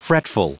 Prononciation du mot fretful en anglais (fichier audio)
Prononciation du mot : fretful